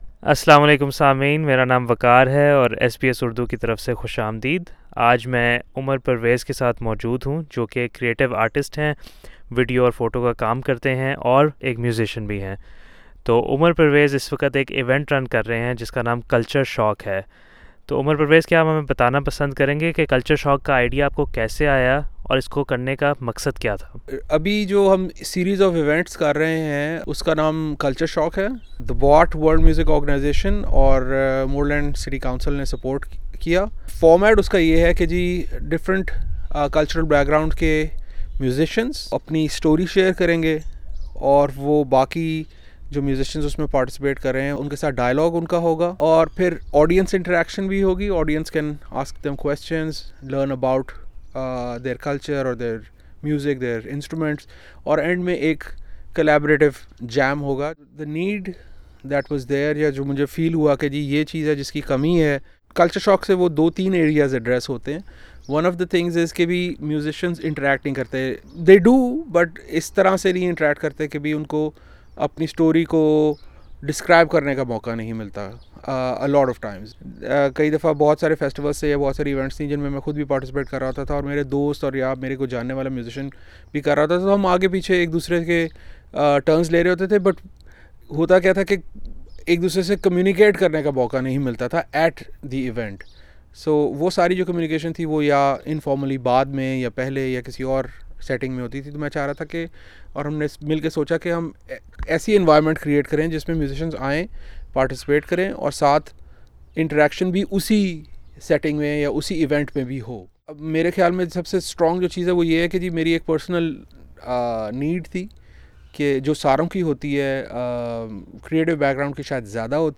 kultur_shok_interview.mp3